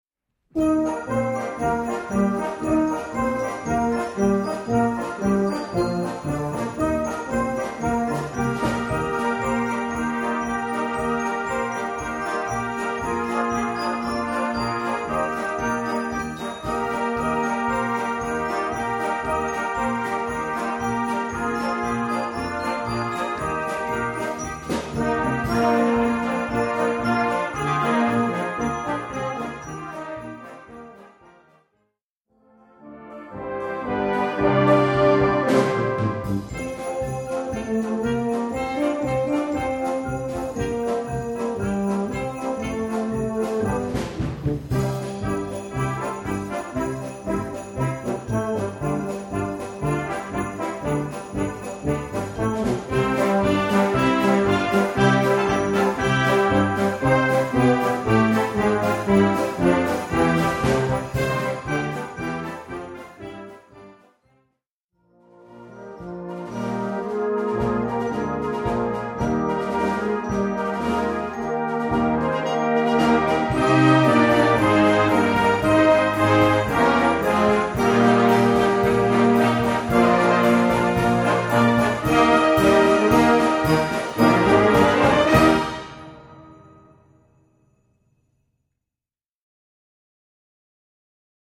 Gattung: Weihnachts-Medley
Besetzung: Blasorchester